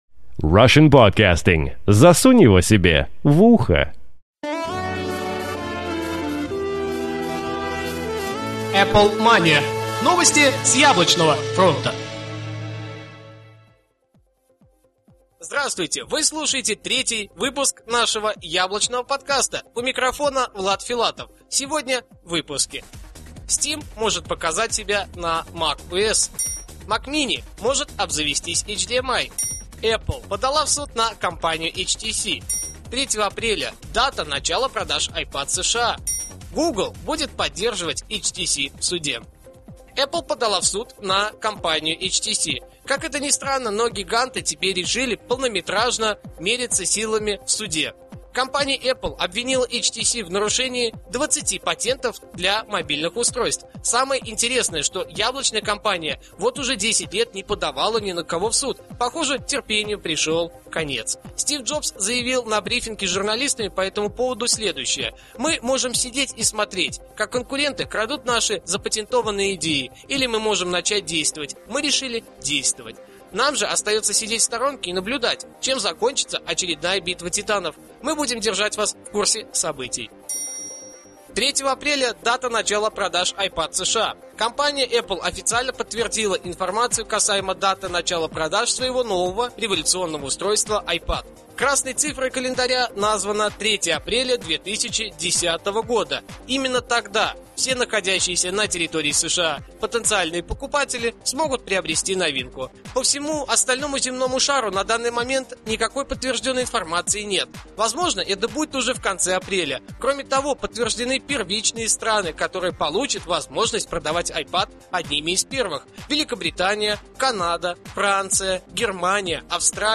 "Apple Mania" - еженедельный новостной Apple подкаст
Жанр: новостной Apple-podcast